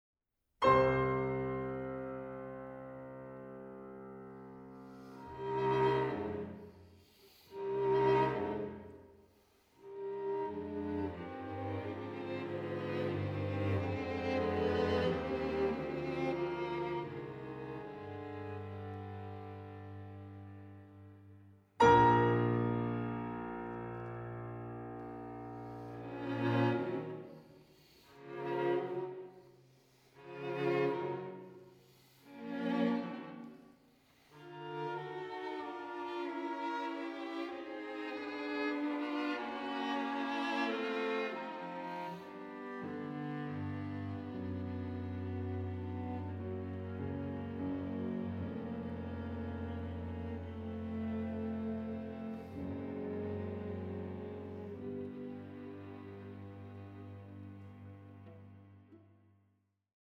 Chamber Music between Romanticism and Exile
I. Allegro non troppo